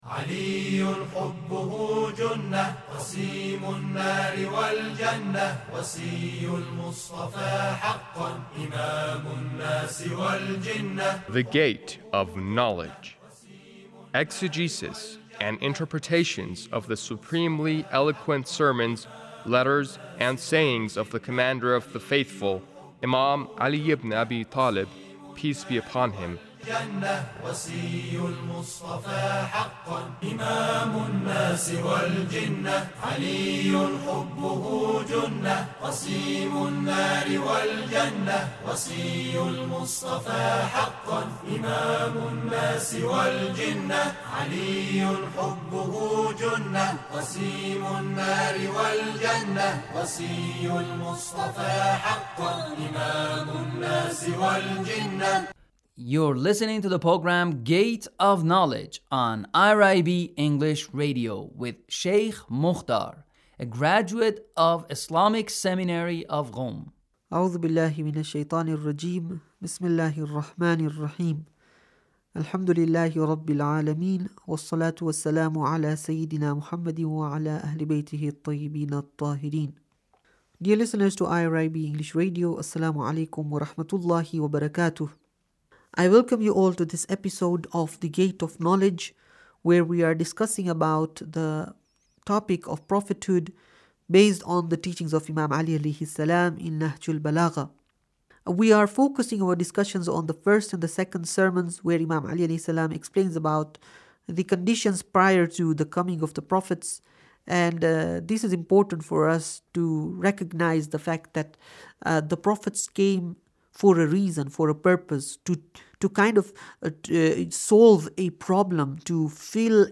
Sermon 2 - Prophethood 32